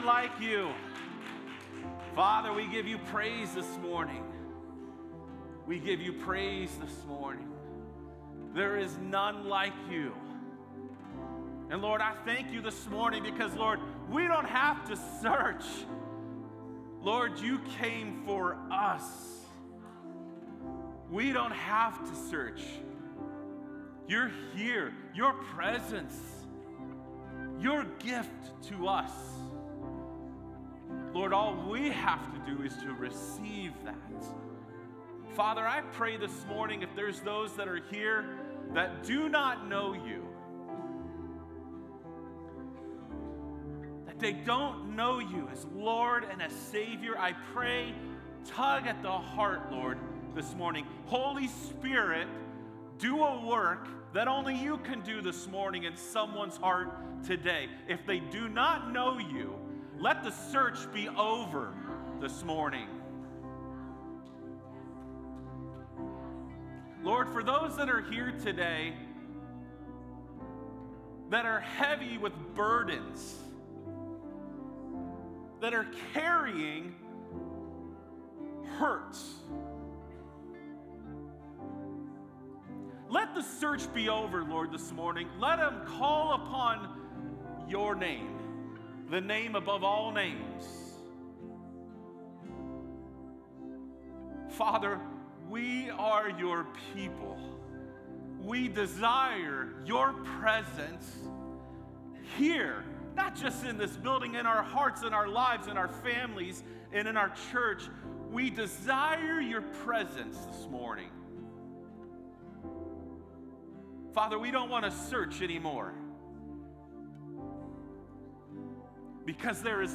A message from the series "Impact."